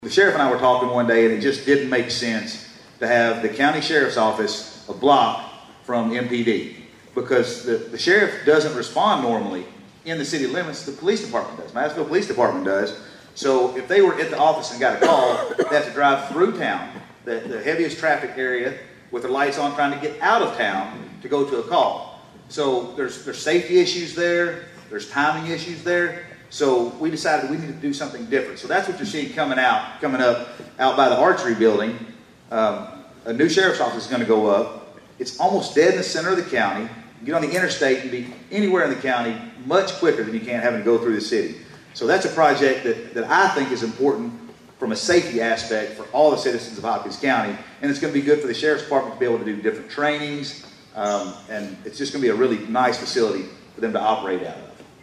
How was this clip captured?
At the State of the Cities and County Address last week